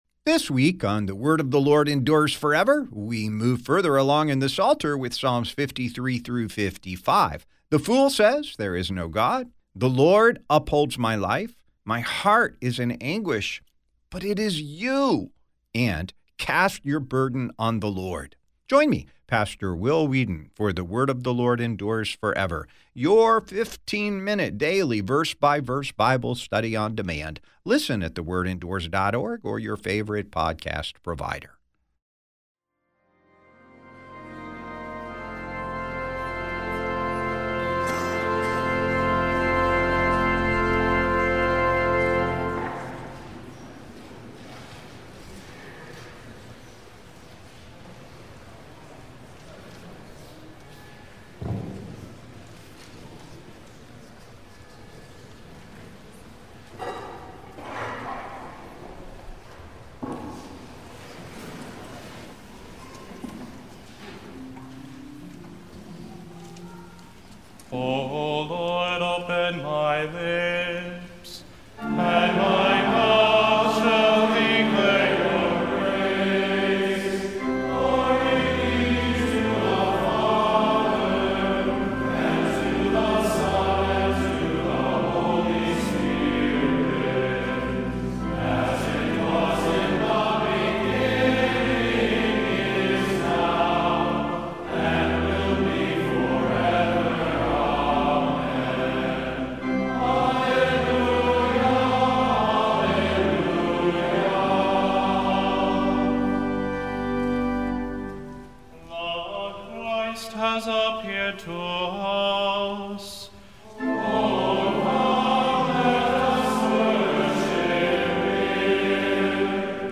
On the campus of Concordia Theological Seminary, Fort Wayne, Indiana.